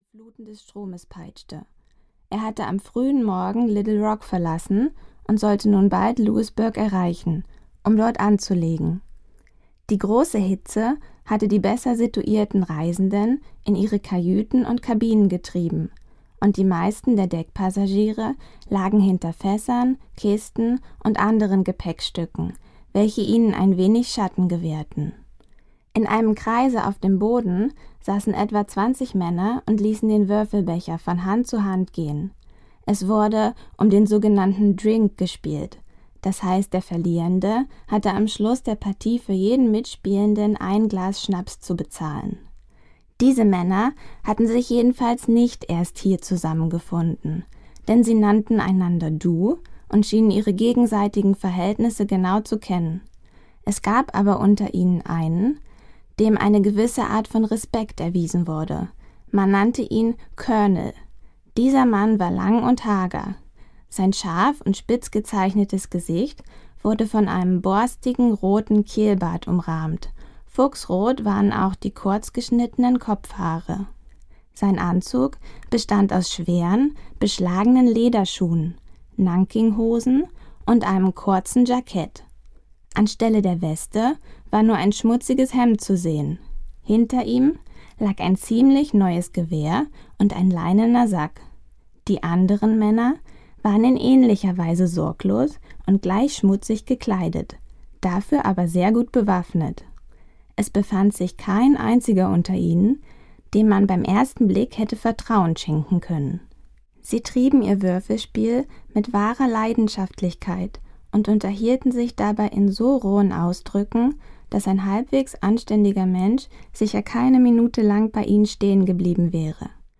Der Schatz im Silbersee (DE) audiokniha
Ukázka z knihy
Román namluvila rodilá mluvčí.